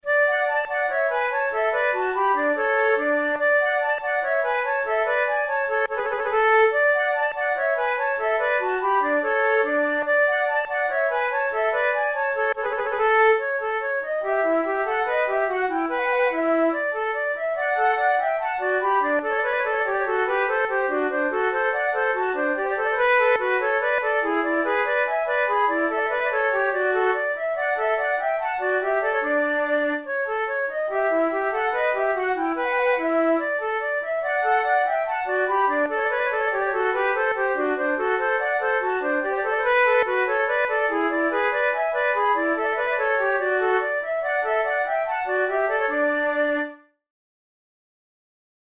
from Sonata for two flutes in D major
This gavotte is the fifth and final movement of a Sonata in D major for two flutes by French Baroque composer Michel Blavet.
Categories: Baroque Gavottes Sonatas Written for Flute Difficulty: intermediate